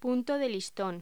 Locución: Punto de listón
Sonidos: Voz humana